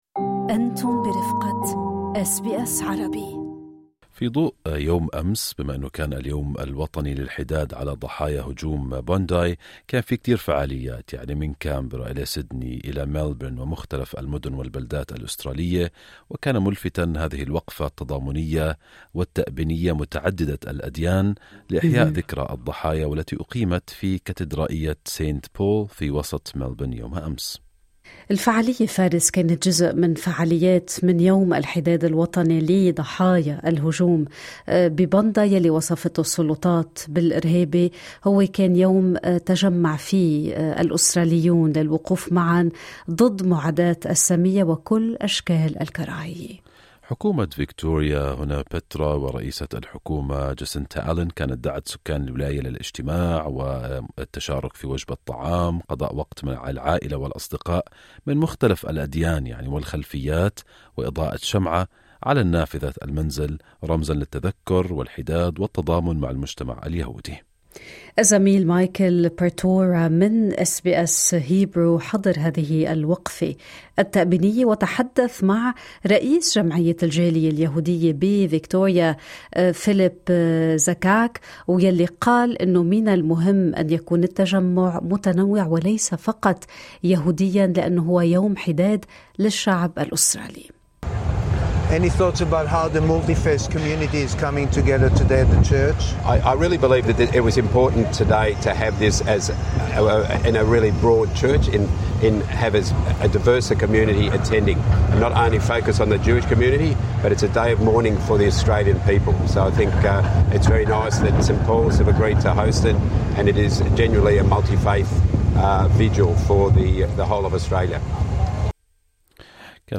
فقد أكد الوزير جهاد ديب، في حديث لـ«أس بي أس عربي»، اعتزازه بجذوره وبالتنوع الذي بُنيت عليه أستراليا، معتبراً أن قوة البلاد تكمن في كونها وطناً لمجتمعات متعددة الثقافات والأديان تعيش معاً باحترام متبادل.
لقراءة محتوى التقرير الصّوتي، اضغط على خاصيّة Transcription في الصورة أعلاه.